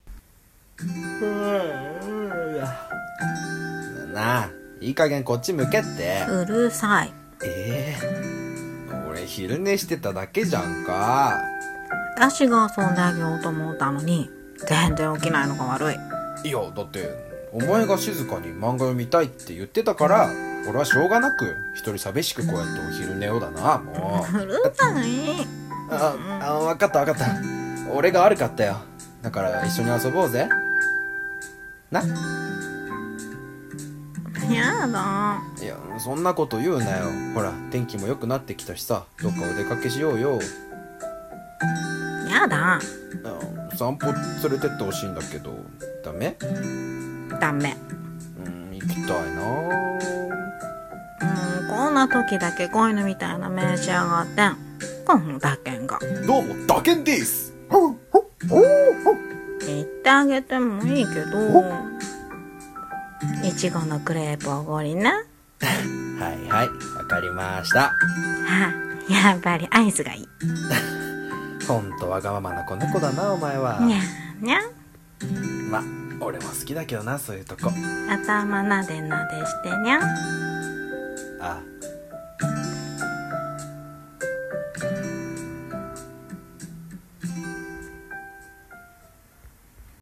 猫系彼女と犬系彼氏「二人声劇」